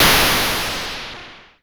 Cwejman S1 - Straight Noise.wav